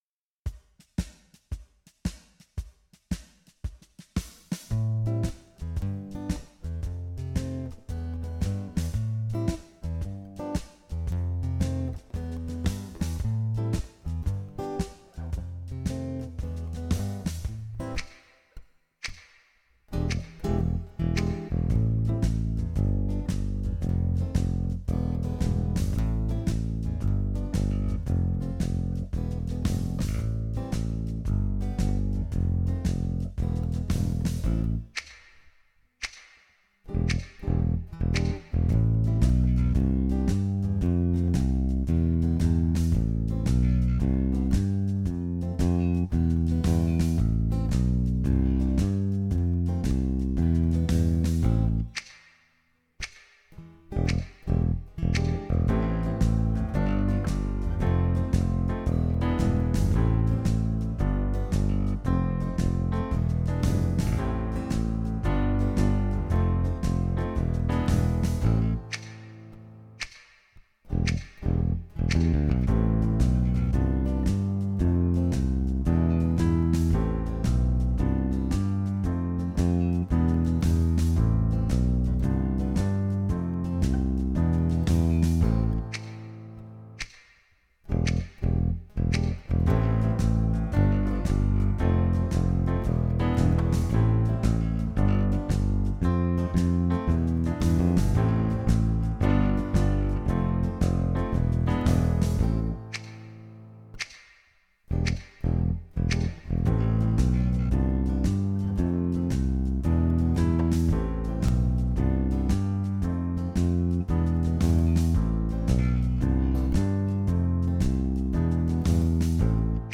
Jam Track
Jam track inspired by